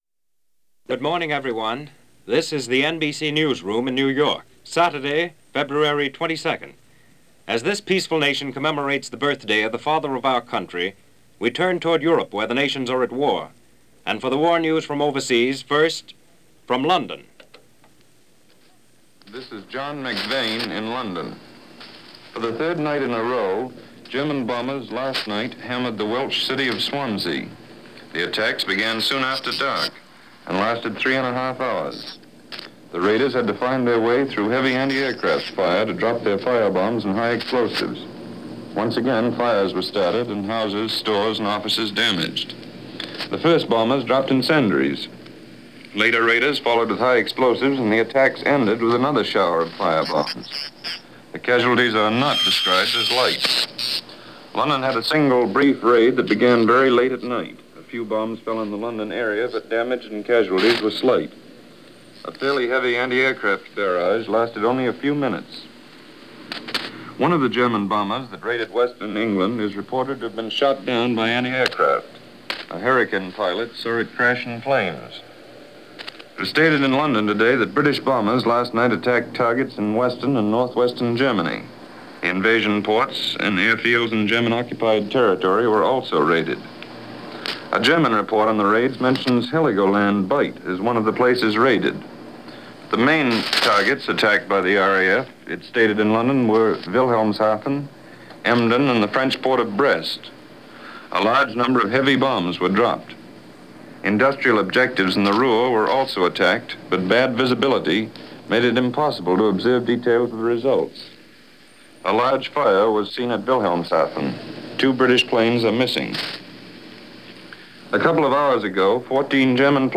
Washington's Birthday - Europe At War: Night Raid On Swansea - February 22, 1941 - Latest reports from News Of The Wold